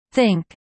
・発音：シィンク